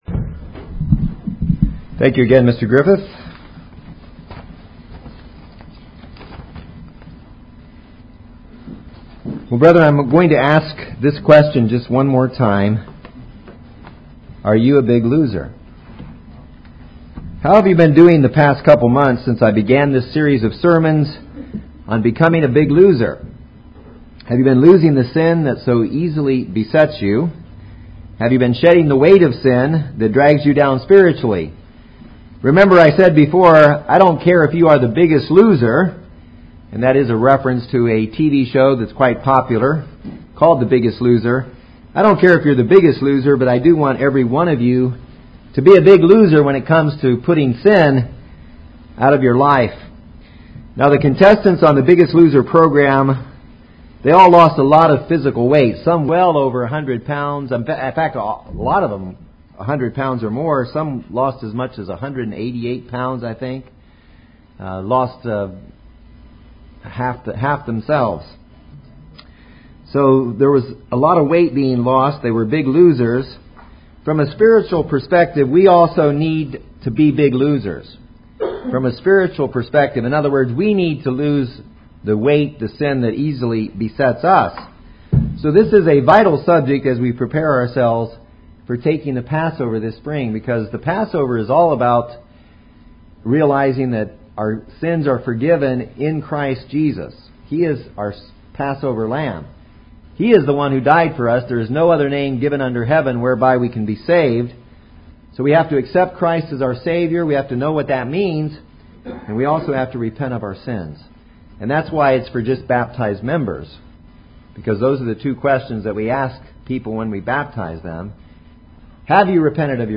How can you become more spiritually fit? This is part 4 of this sermon series.